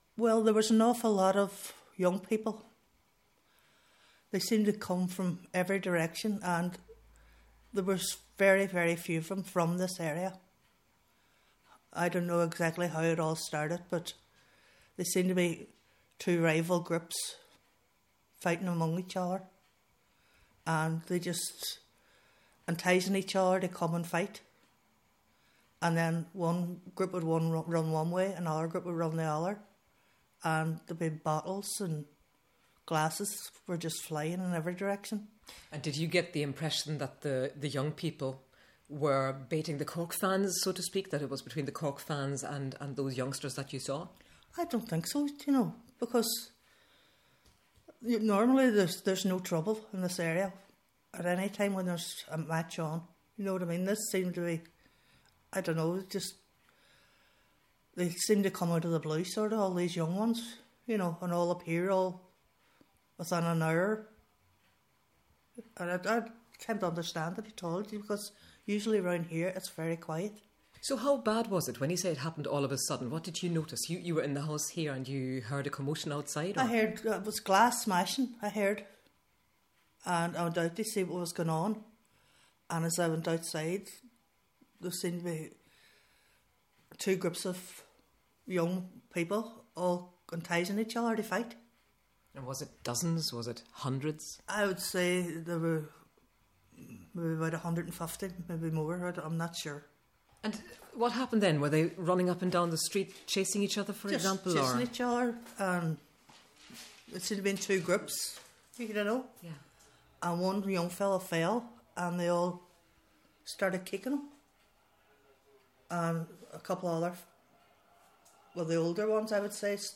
The woman - who didn't want to be named - said it was a frightening experience.